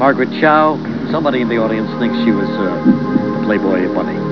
The popular game show Who Wants To Be a Millionaire? (I call it "Desperate Capitalists") aired a question on January 9, 2000 related to, you guessed it, Margaret Cho. It was by the hand of the comedy god that I happened to catch this AND have a tape in the VCR ready to go.